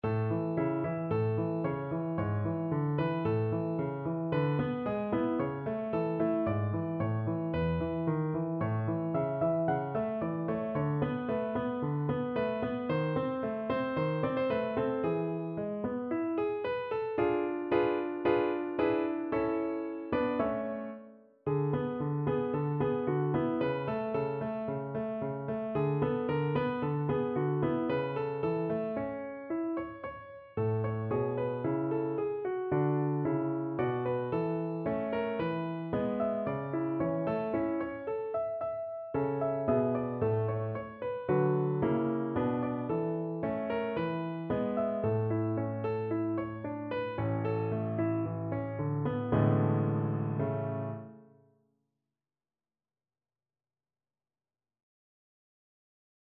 Piano version
No parts available for this pieces as it is for solo piano.
2/4 (View more 2/4 Music)
~ = 56 Affettuoso
Piano  (View more Intermediate Piano Music)
Classical (View more Classical Piano Music)